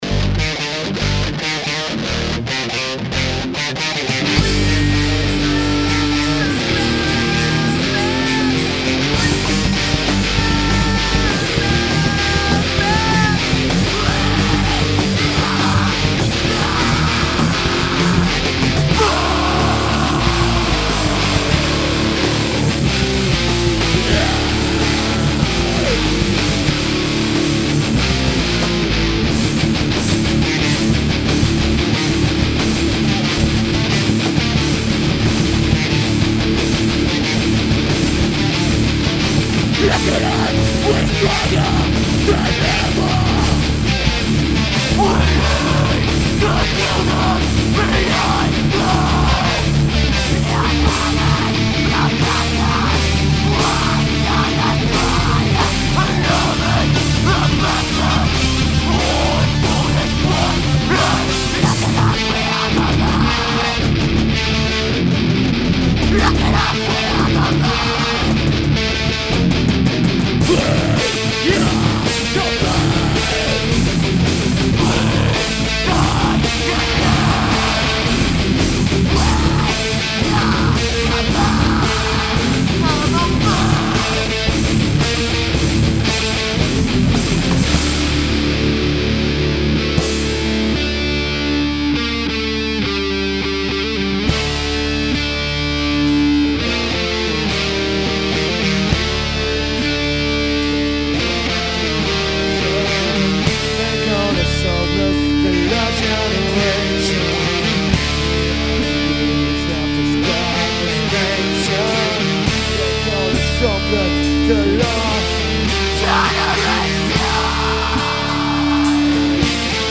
Drums
Bass
Vocals